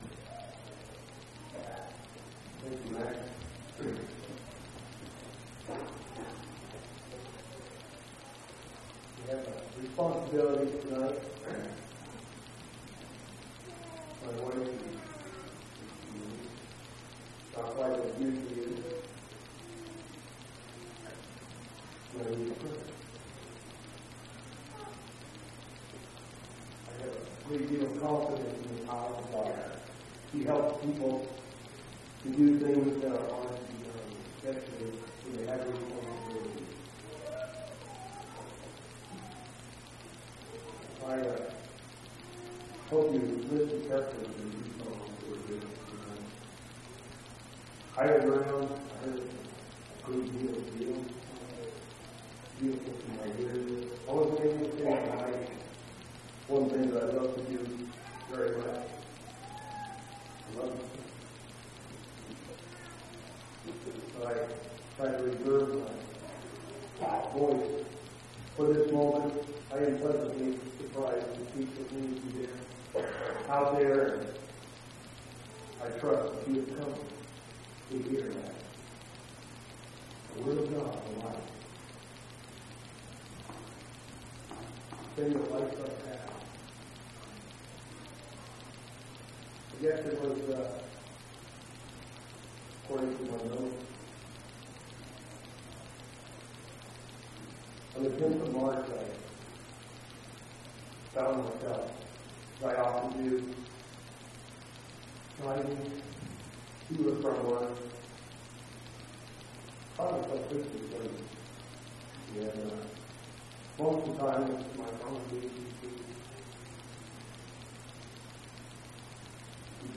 Event: General Church Conference
audio-sermons